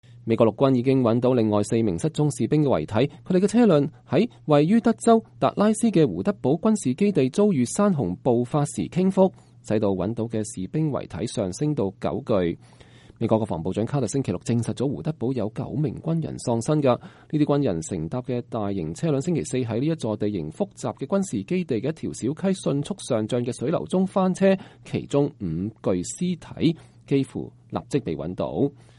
美國陸軍少將約翰烏博蒂在德州胡德堡基地外對傳媒講話